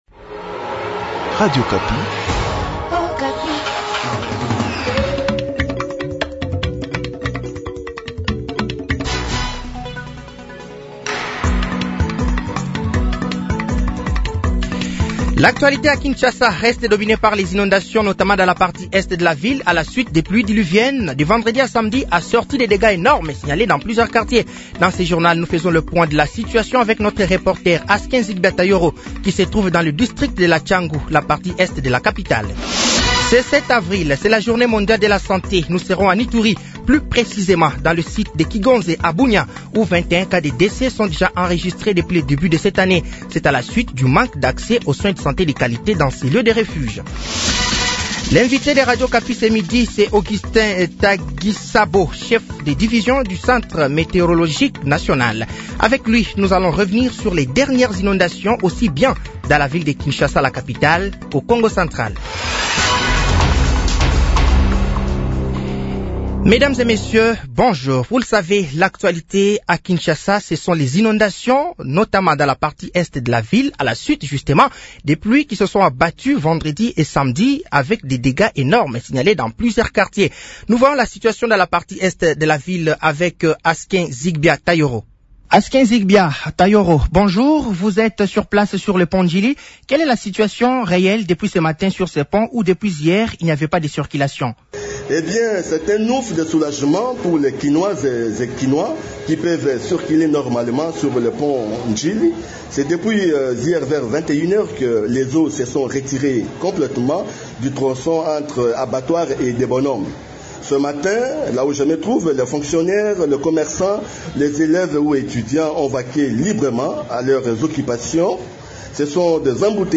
Journal français de 12h de ce lundi 07 avril 2025